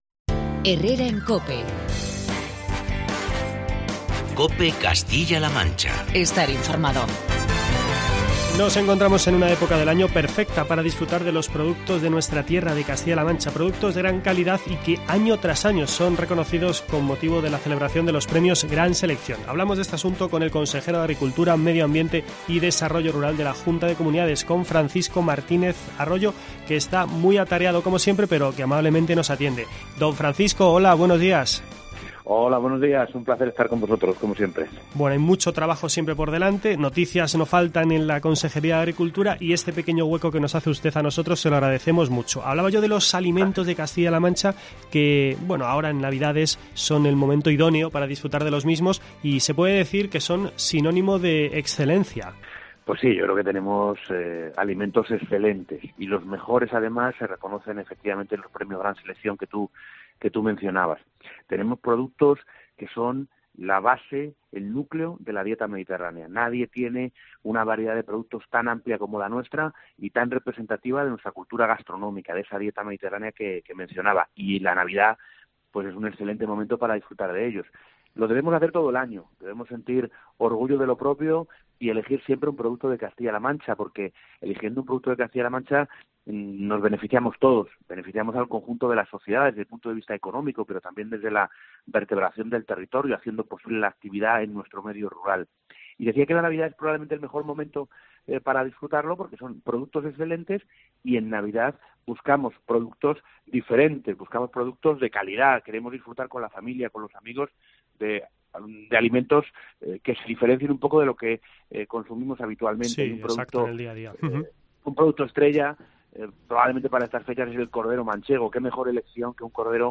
Entrevista con el consejero de Agricultura, Medio Ambiente y Desarrollo Rural, Francisco Martínez Arroyo.